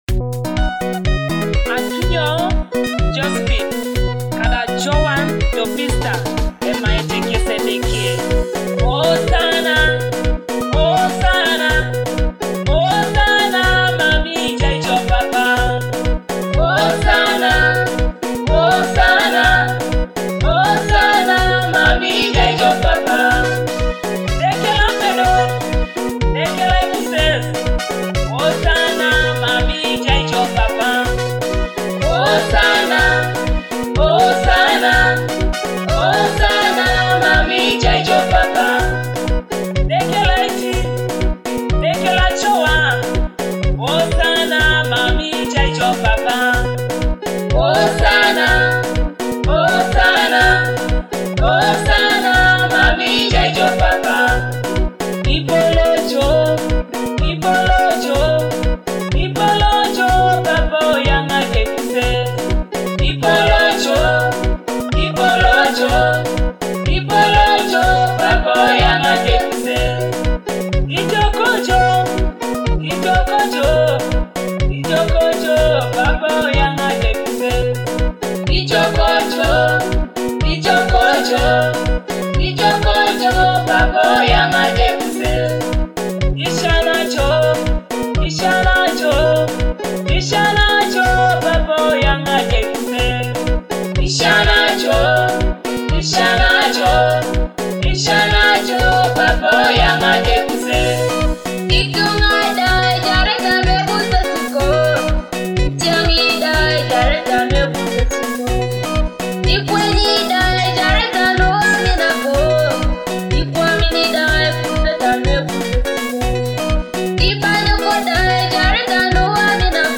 a soul-stirring gospel praise song that uplifts hearts